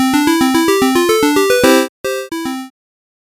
8-bit square wave FTW!